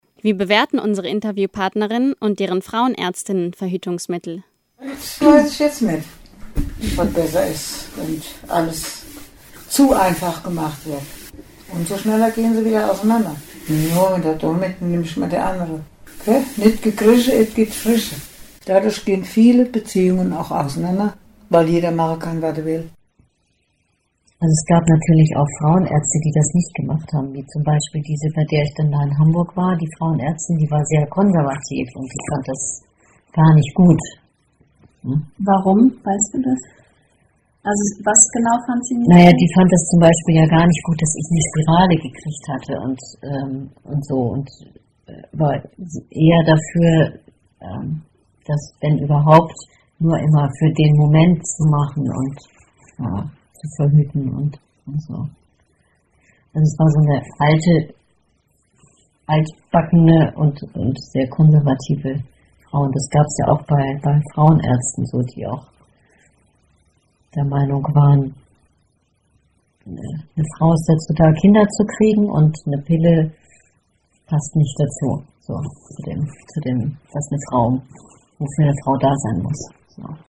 Diesen Fragen widmen sich die Interviews dreier Frauen der Jahrgänge 1935, 1943 und 1955: So werden beispielweise Frauenarzt/-ärztinnenbesuche, Erfahrungen mit Abtreibung, Auseinandersetzungen bezüglich Sexualmoral, aber auch gesellschaftliche Umbrüche in ihren Erzählungen geschildert.
Die Interviewausschnitte sind absteigend nach Alter zusammengeschnitten.